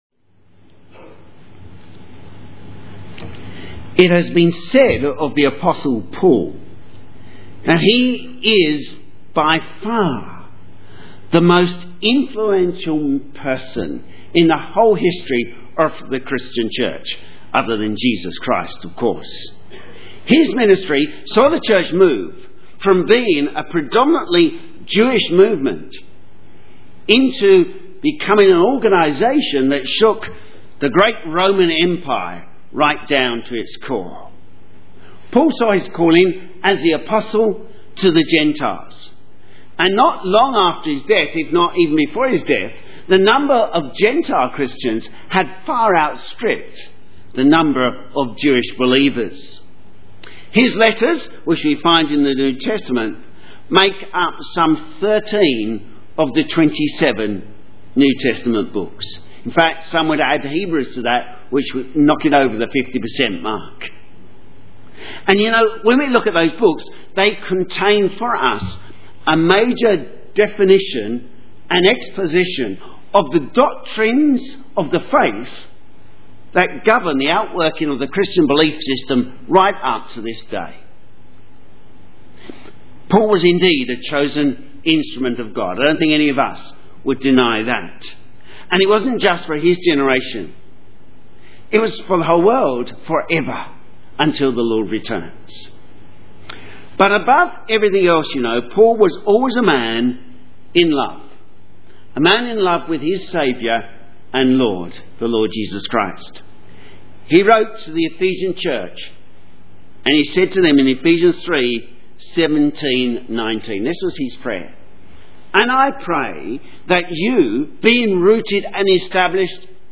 Sermon
Why still a believer series 5 Paul Persuaded and Convinced Acts 26:1-32 Synopsis A look at the life of Paul with regard to the question, Why are you still a follower of Jesus? Gives insights into Paul's experiences as well as providing a strong evangelistic address. Keywords Evangelistic sermon.